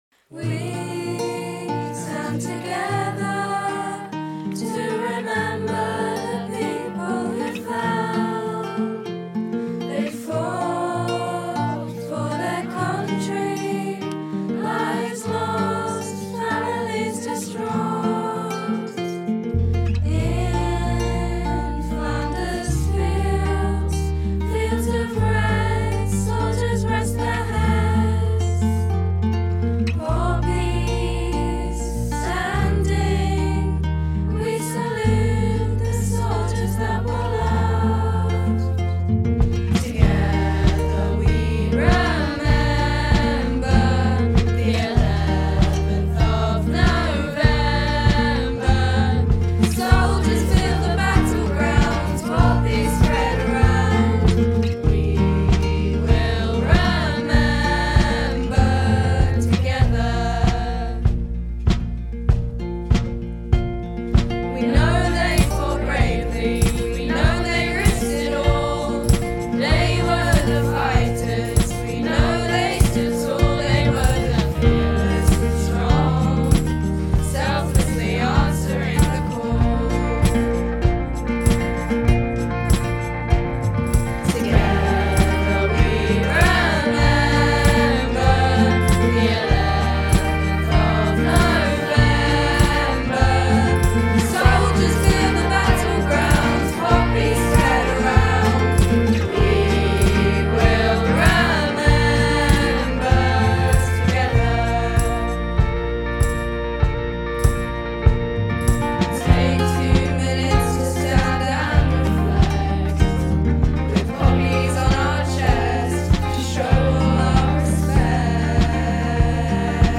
Listen below to the recording made by our students at a London recording studio as a result of their project with The Never Such Innocence Project.